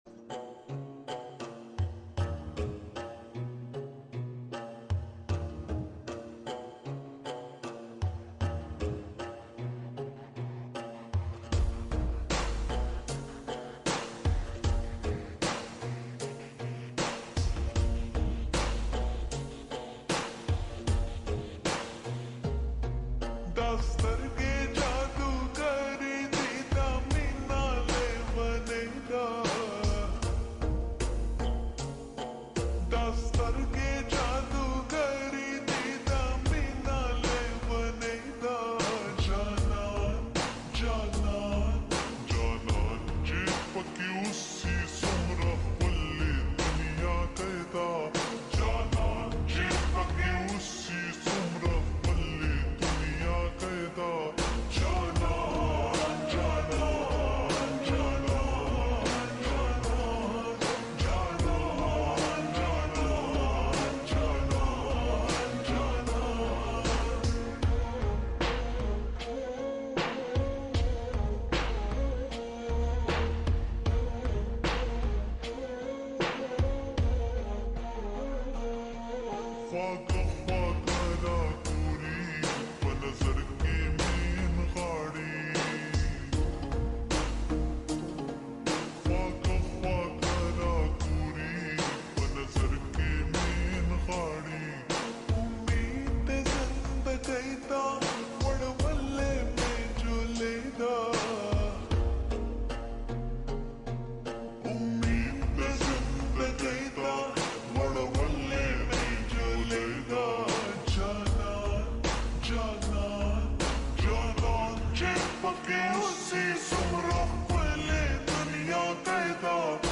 Pashto Full Song ❤🎧👊🎶🎼 Slowed music